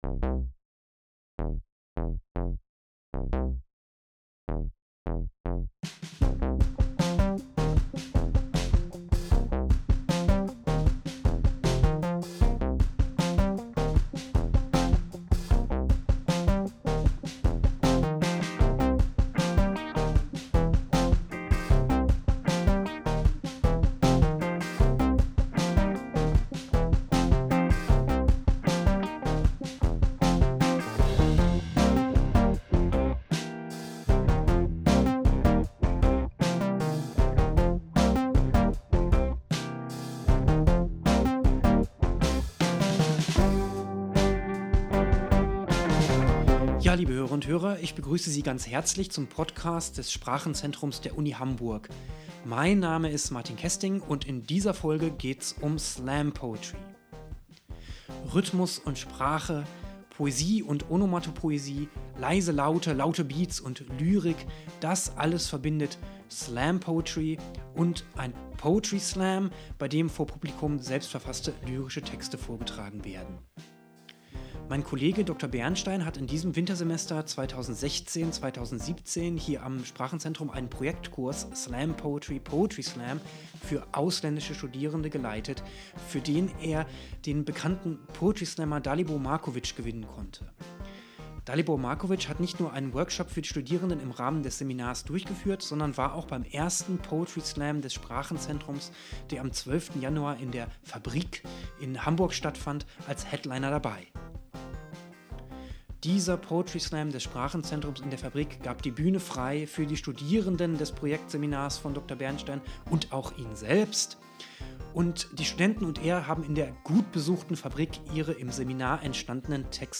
Für unseren Podcast lesen uns die Studierenden ihre Texte noch einmal selbst vor.